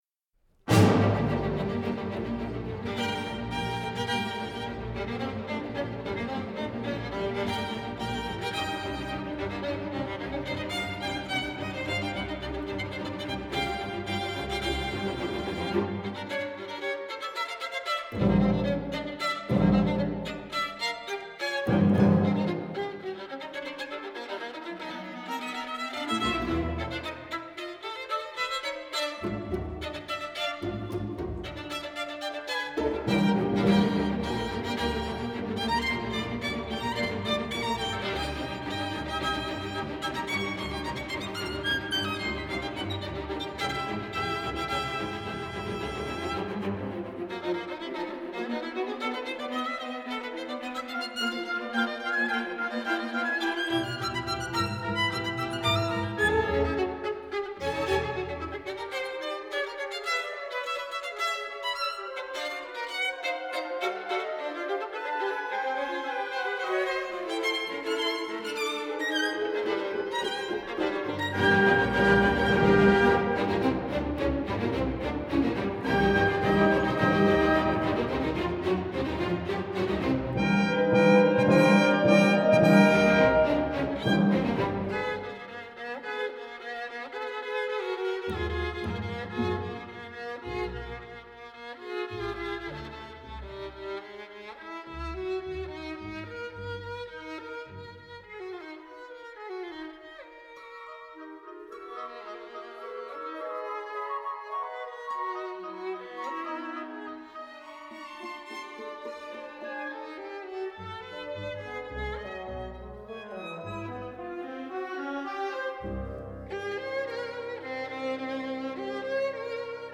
Moisey+Vaynberg+Koncert+dlya+skripki+i+orkestra+Op67.ogg